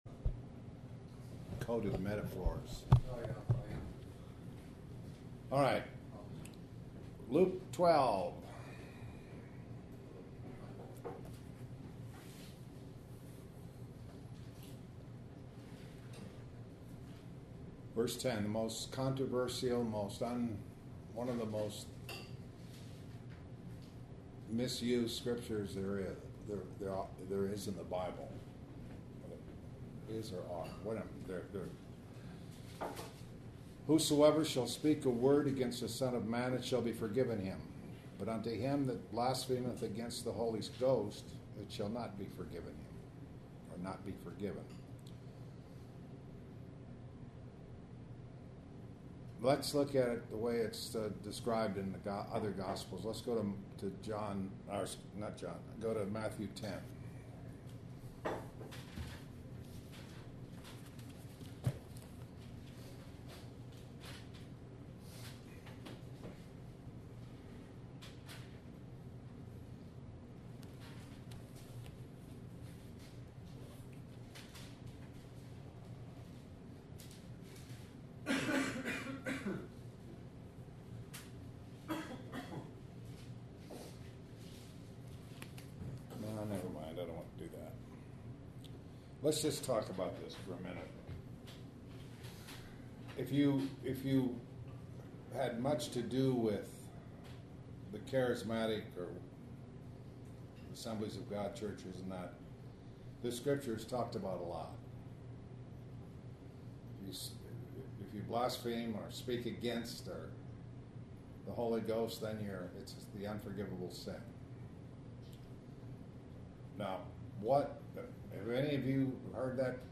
The Paraclete / Comforter This entry was posted in Morning Bible Studies .